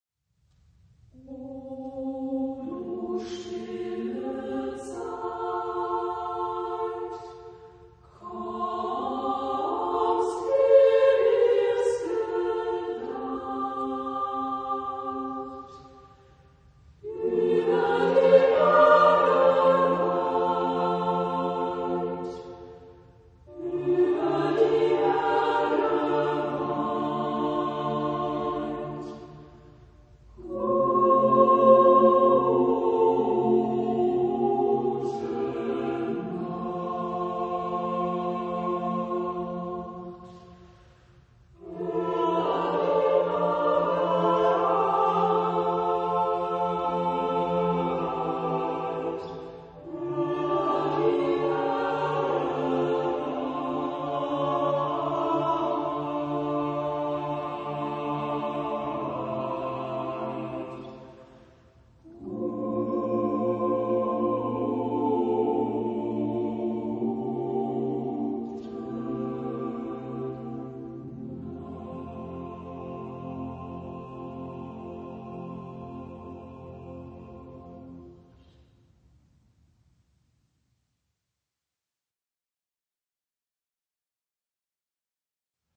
Genre-Style-Form: Partsong ; Secular
Type of Choir: SATB  (4 mixed voices )
Tonality: F major